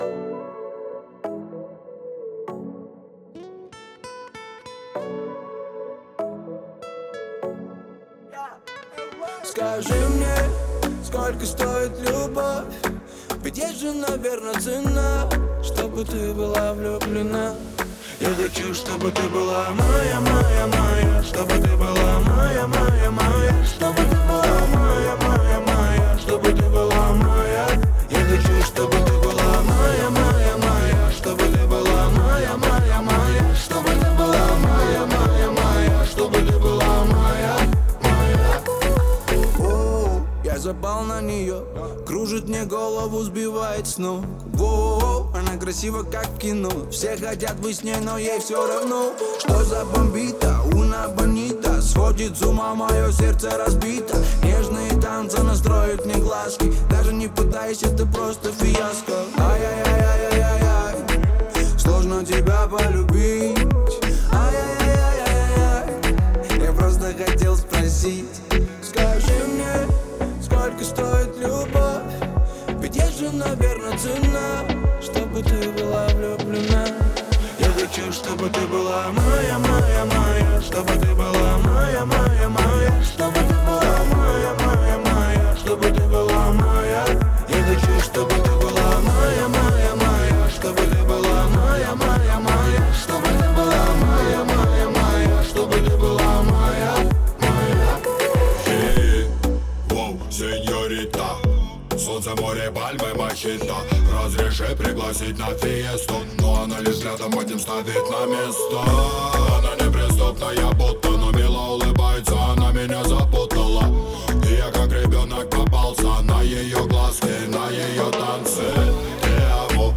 это яркая и запоминающаяся композиция в жанре поп